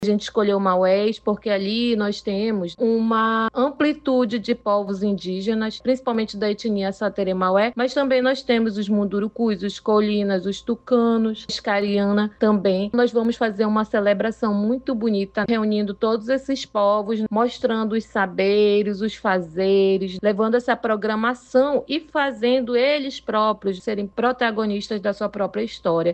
SONORA-1-FESTIVAL-INDIGENA-MAUES-.mp3